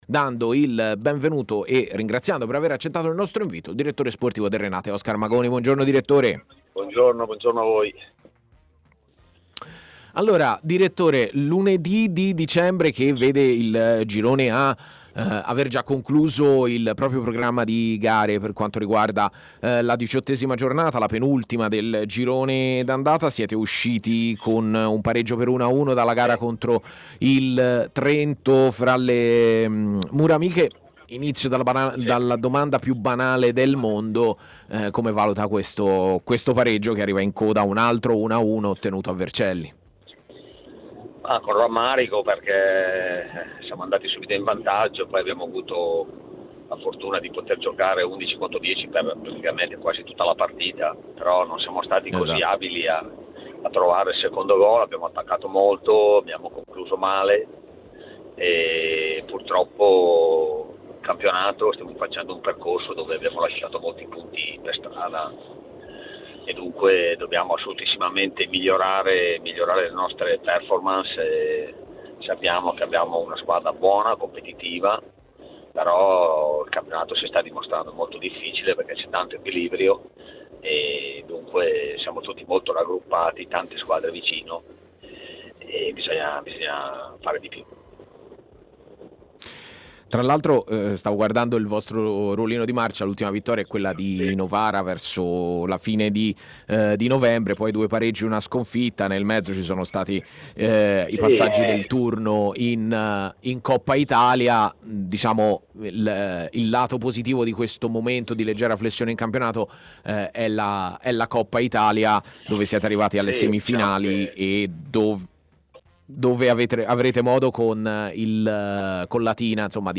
TMW Radio